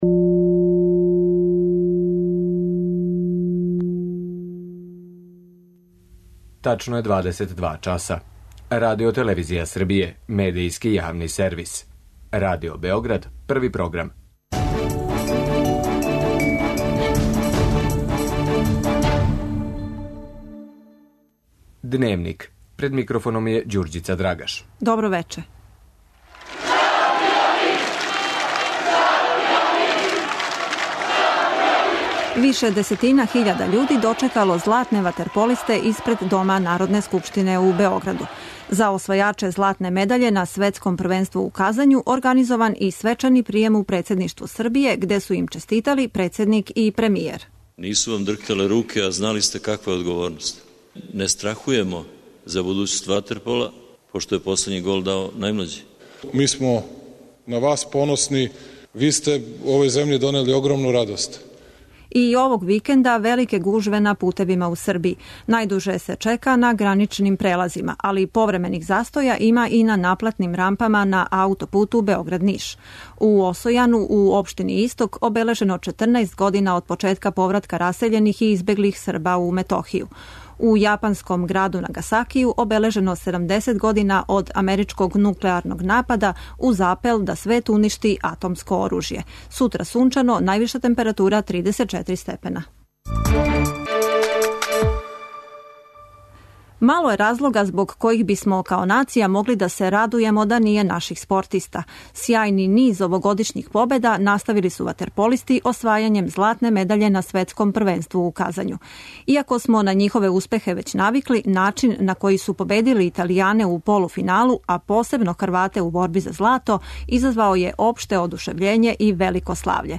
У Дневнику преносимо атмосферу са дочека који се за златне ватерполисте организује испред Дома народне скупштине.